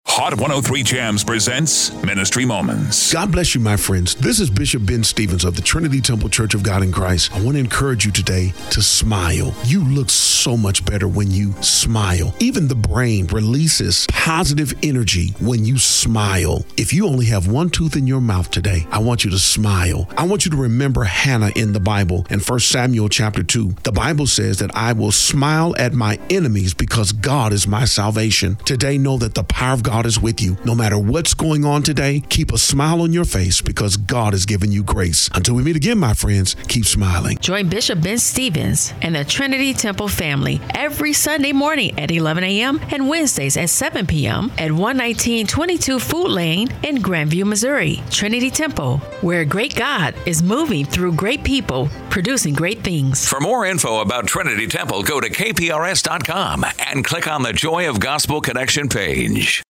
Radio Broadcast